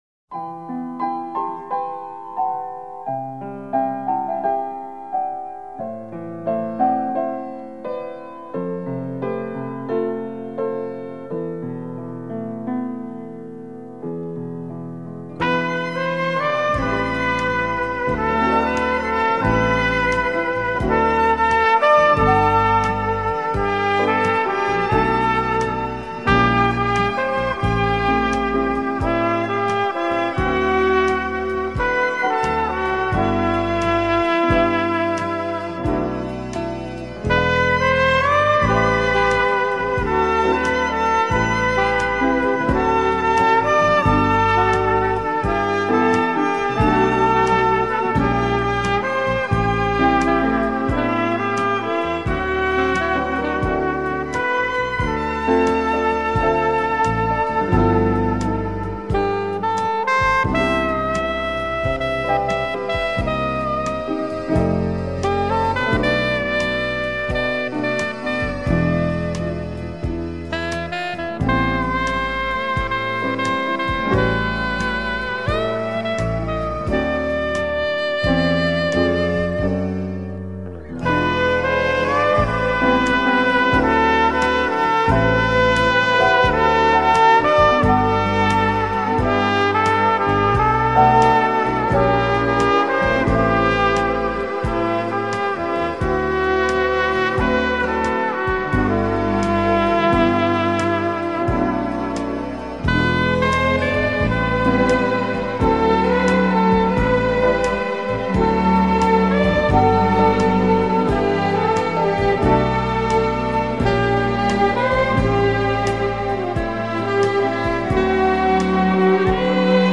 موسیقی بیکلام موسیقی متن